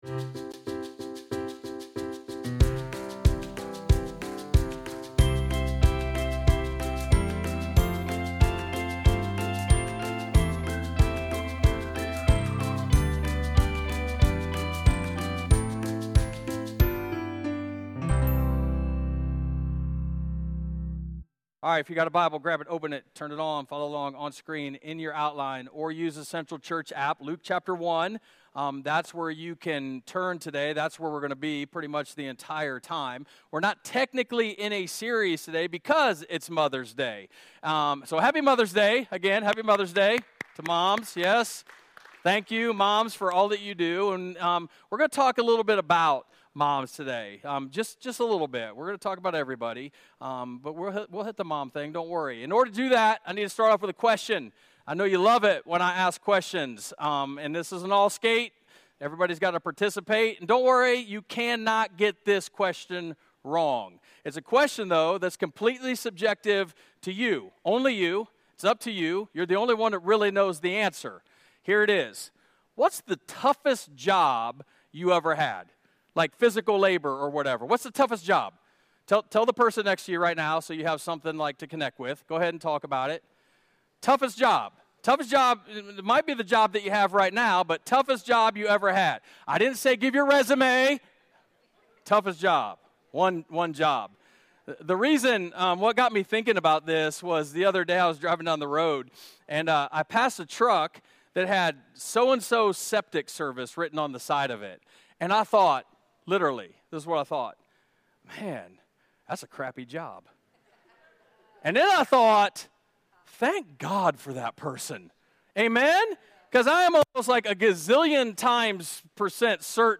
While this Sunday's sermon was based on Mother's Day, it applied to EVERYONE! It focused on how we can know God's will for our lives.